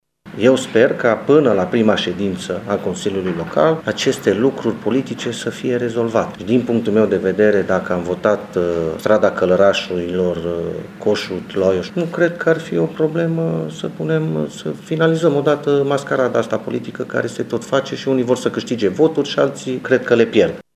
În replică, viceprimarul Claudiu Maior speră că acest gen de discuţii sunt doar provocări şi crede că, până la prima şedinţă de Consiliu Local, factorii politici să ajungă la un consens pentru binele oraşului: